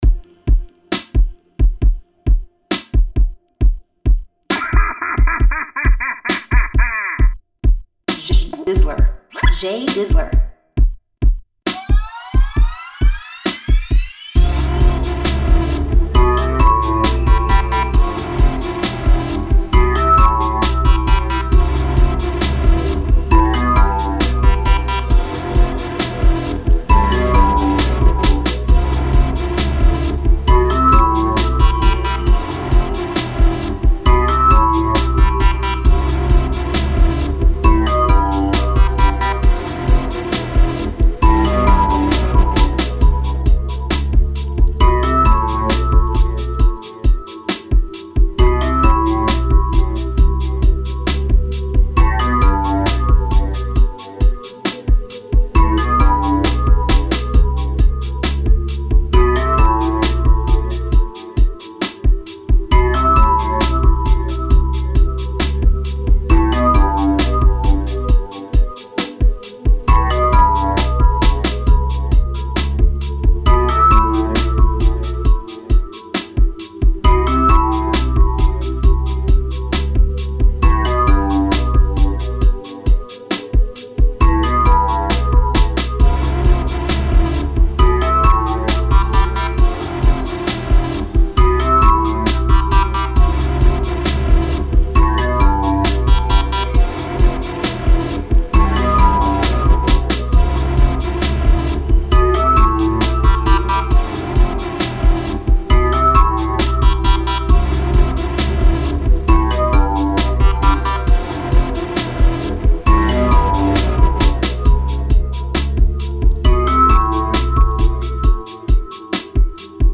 February Beat Tape!